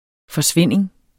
Udtale [ fʌˈsvenˀeŋ ]